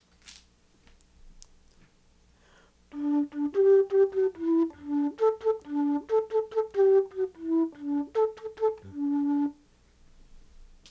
The following audio files illustrate the difference in sound between the instruments.
Click here to download a windows audio file of the first part of the tune 'L'Homme Arme' being played on a baroque flute.
baroque_flute_lhomme_arme.wav